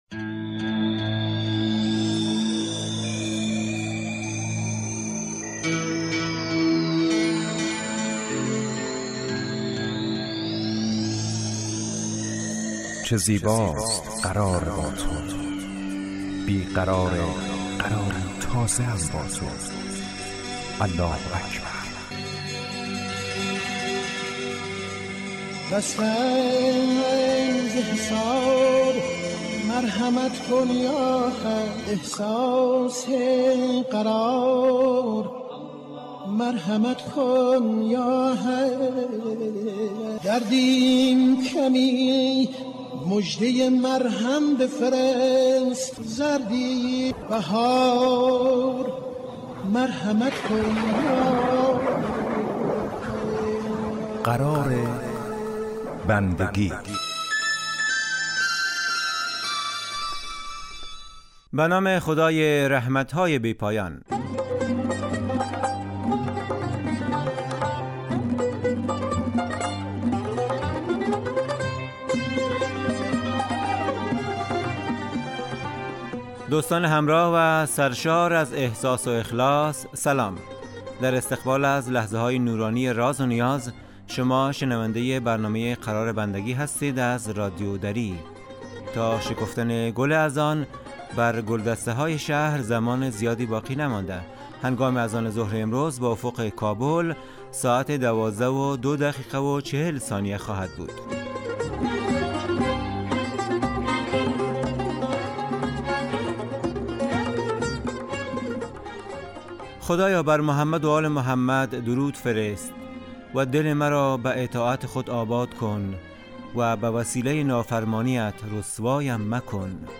برنامه اذانگاهی